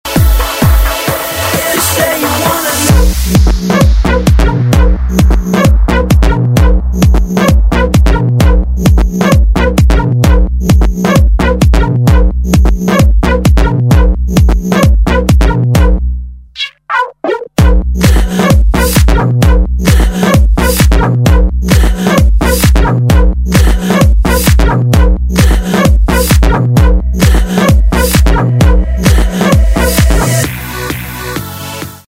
Назад в Мр3 | House